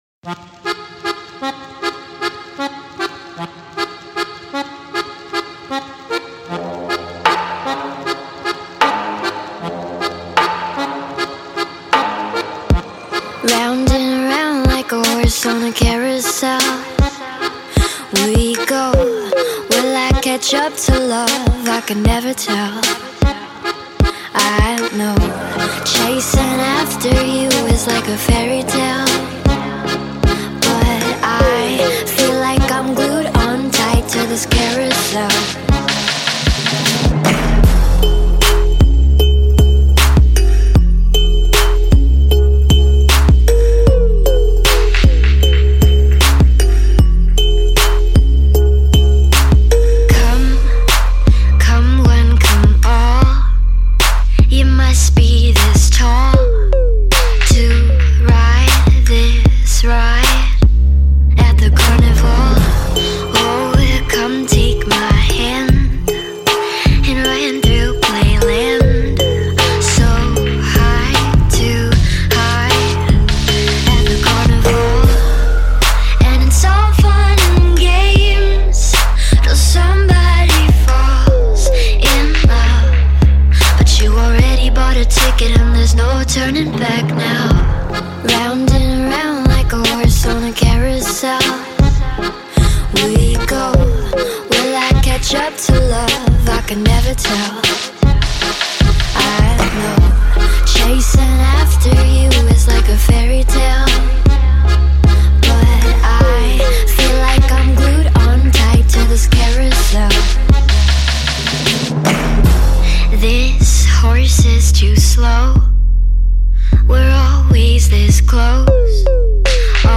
БАСЫ в МАШИНУ , спокойный бас в авто